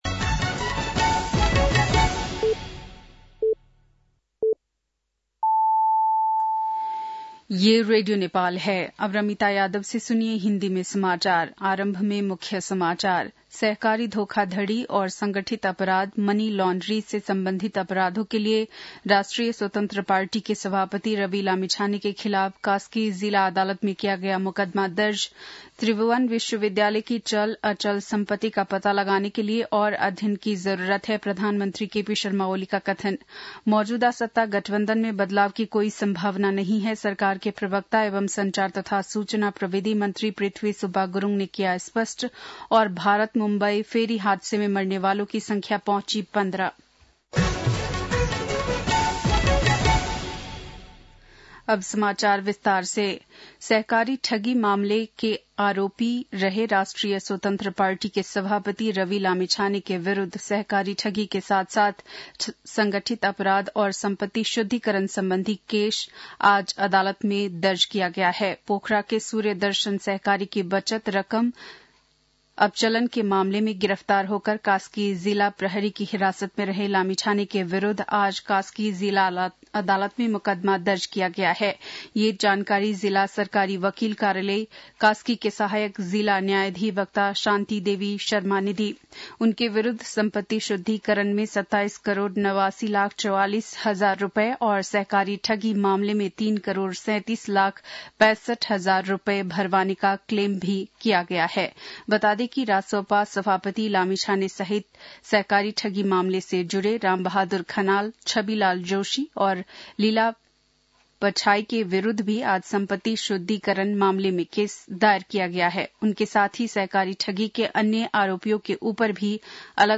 बेलुकी १० बजेको हिन्दी समाचार : ८ पुष , २०८१
10pm-hindi-news-9-07.mp3